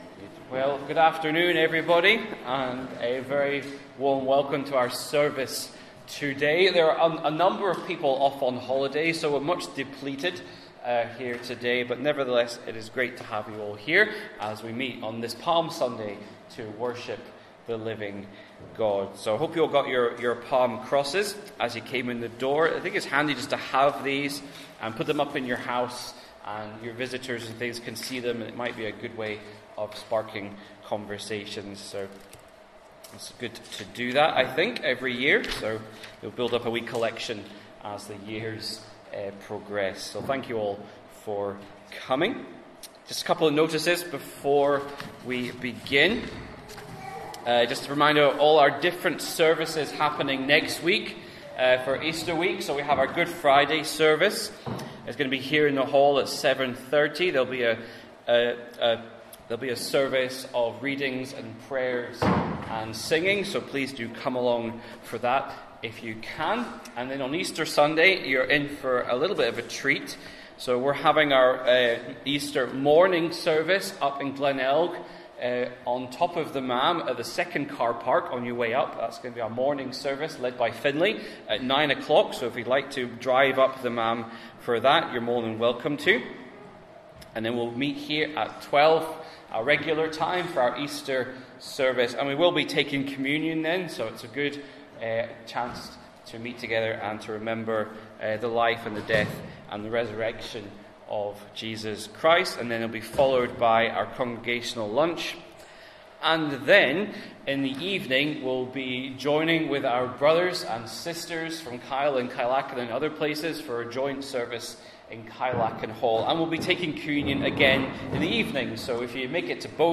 12-Noon-service.mp3